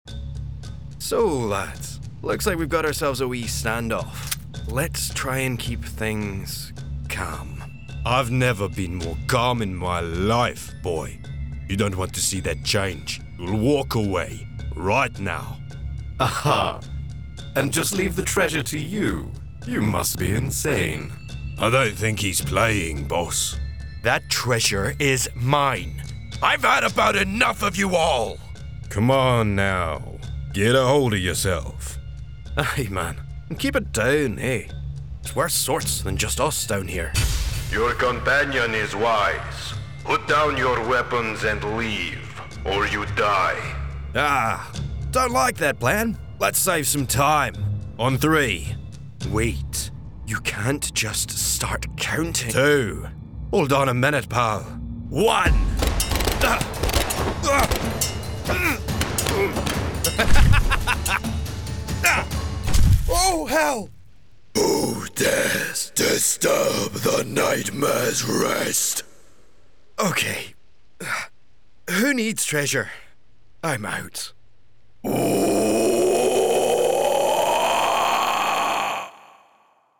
Animation Reel
• Native Accent: Scottish
• Home Studio